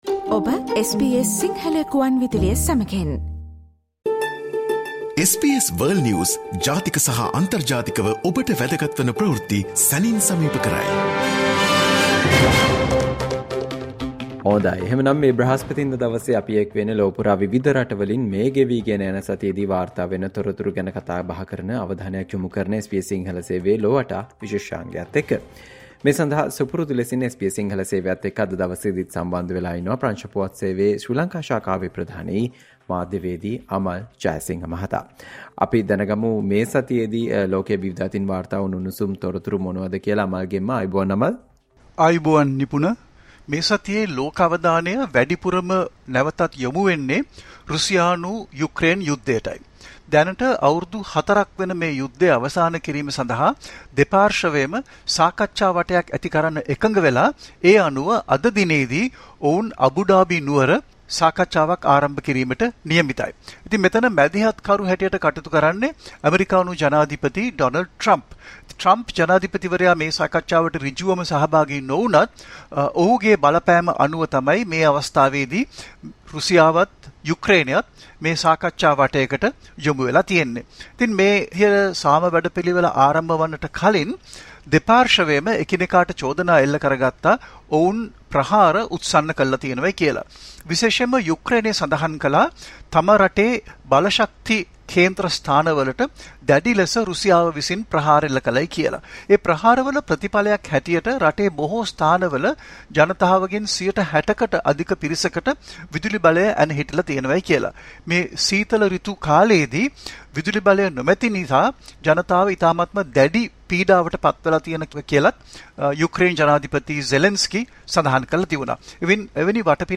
SBS සිංහල සේවය ඔස්සේ ඔබ වෙත ගෙන එන සතියේ විදෙස් පුවත් විග්‍රහය ‘ලොව වටා’ විශේෂාංගය.